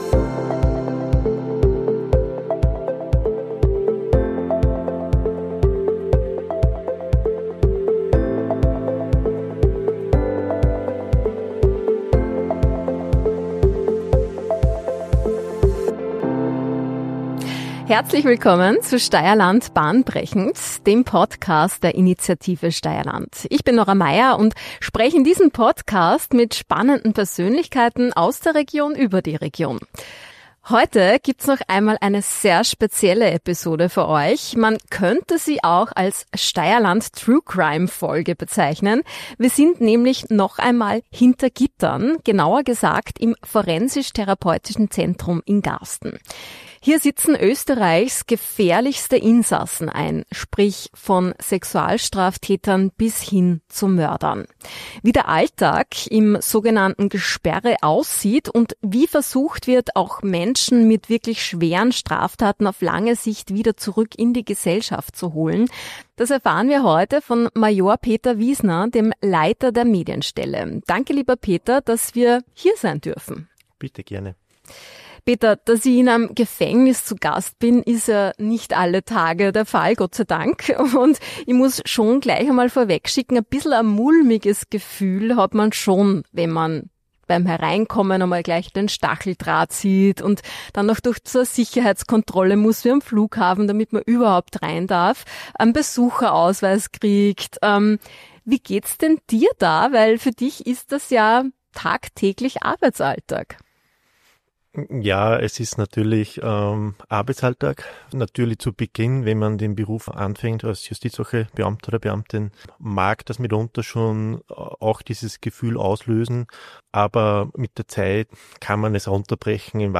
Live mitgehörte Funksprüche geben einen unmittelbaren Einblick in den Ablauf des Vollzugs. Es geht um Verantwortung, Strukturen und um grundlegende Fragen wie: Was bedeutet „lebenslang“ wirklich?